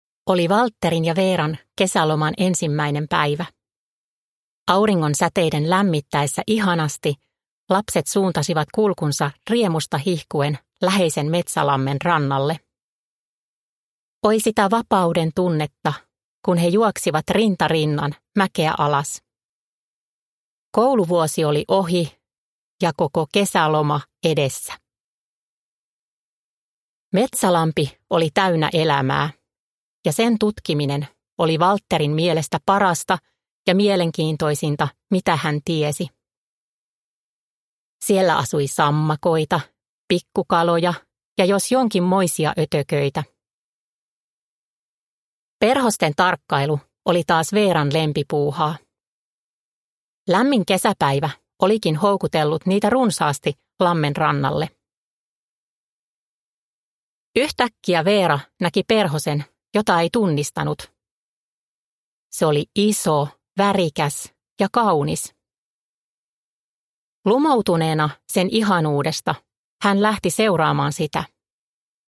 Valtteri ja Veera: Metsä puhuu -iltasatu – Ljudbok